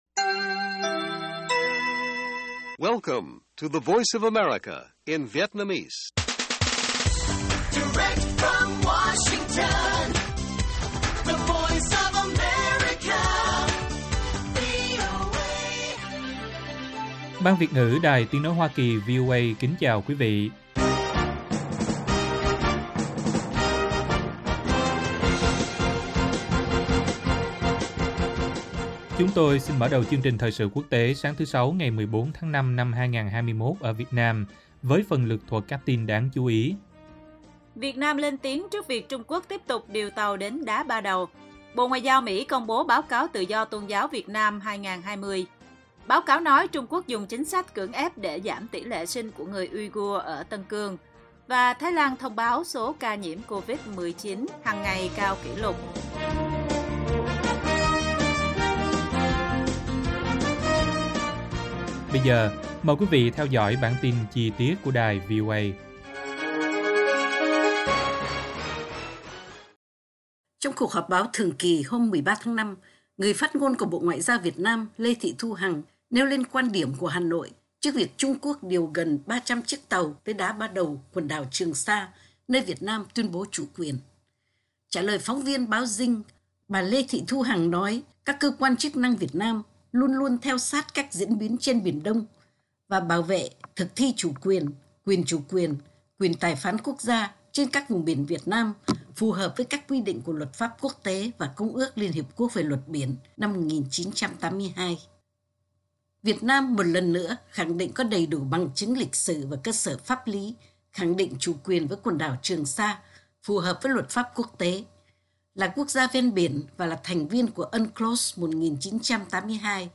Bản tin VOA ngày 14/5/2021